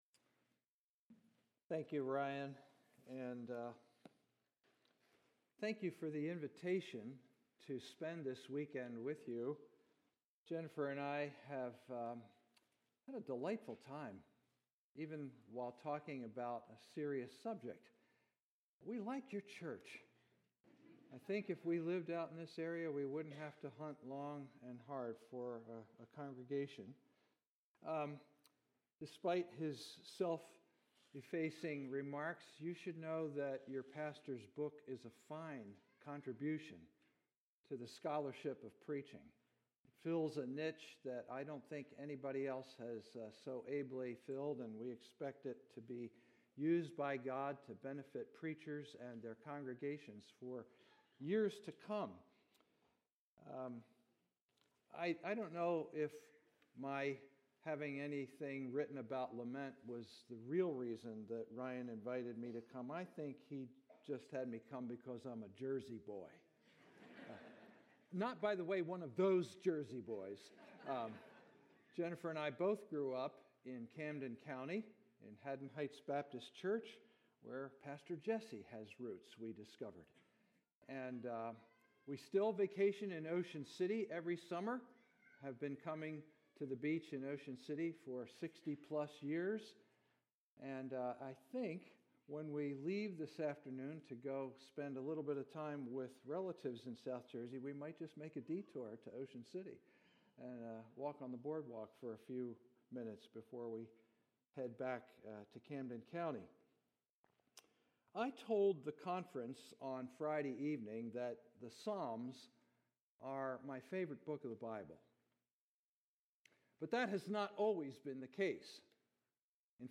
Teaching from the Green Pond Bible Chapel Spiritual Life Conference.